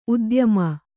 ♪ udyama